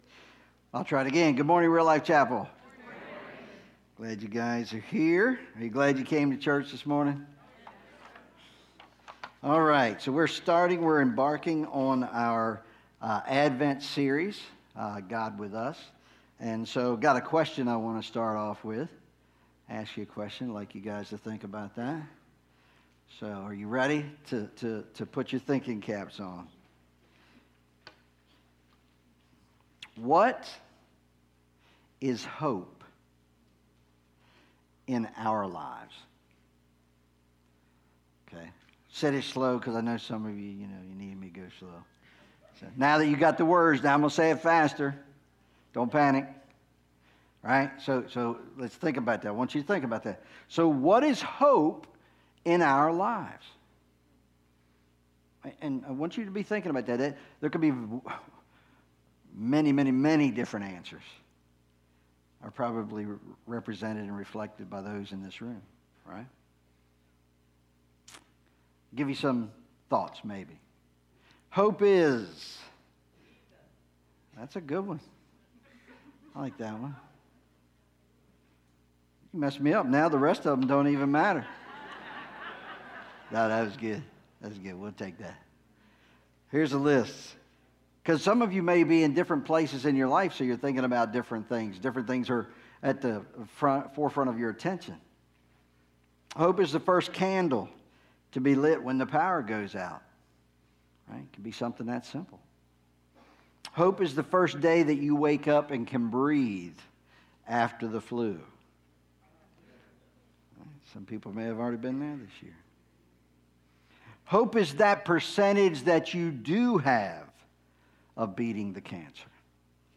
Service Type: Sunday Mornings